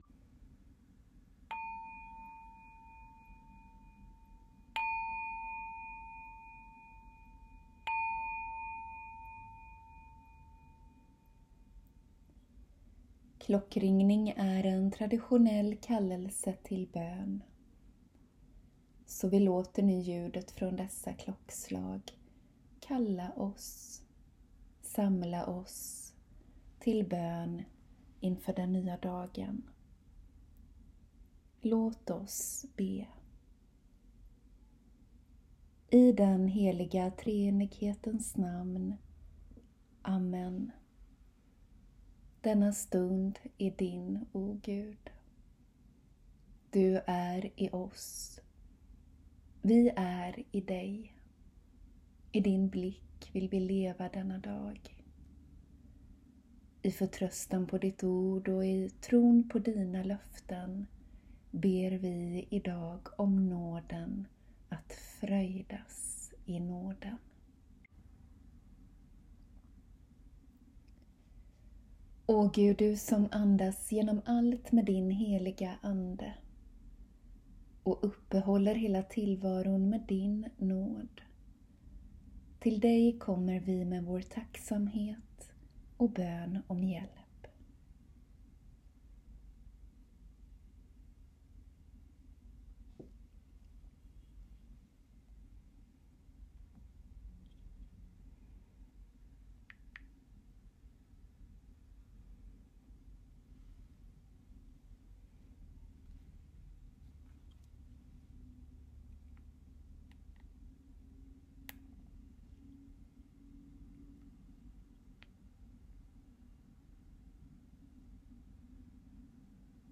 Bön vid dagens början (3 min)